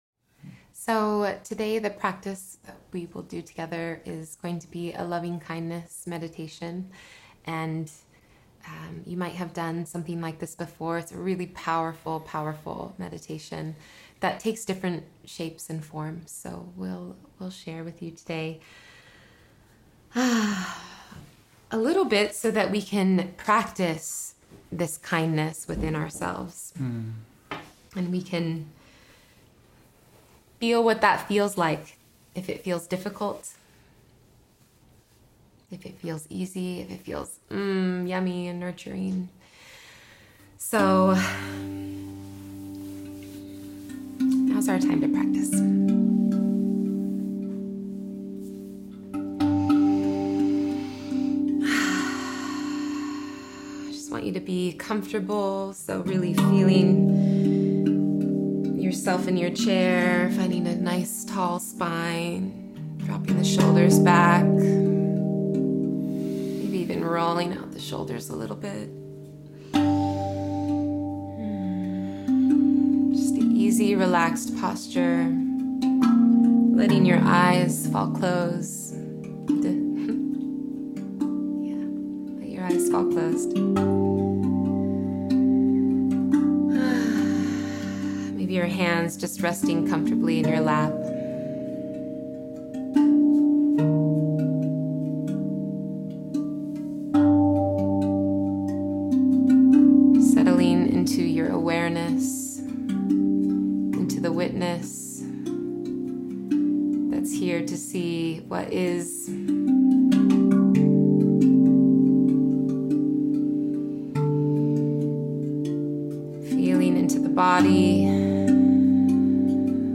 In our final practice, we’ll guide you through a loving-kindness meditation that begins with yourself and then extends out to others—friends, strangers, and even those you may disagree with. We’ll work on cultivating feelings of goodwill and compassion, softening any resentment or tension that might linger.